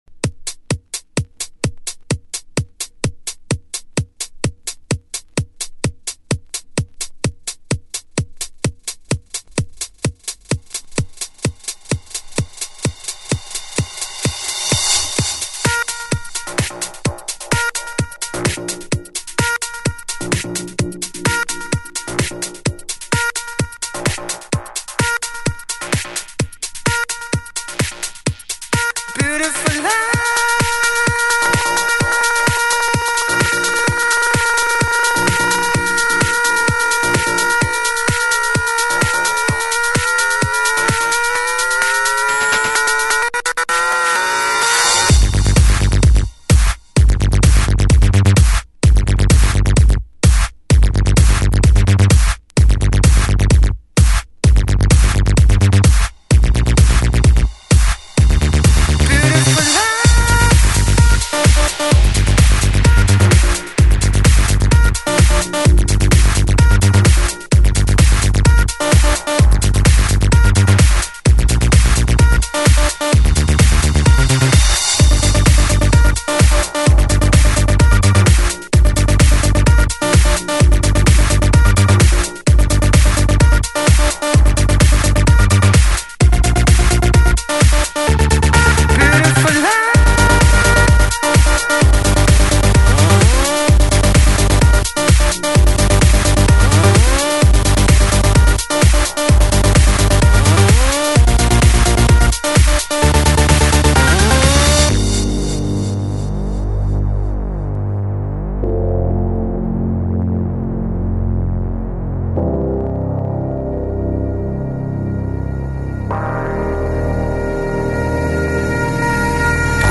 Жанр:Electro/House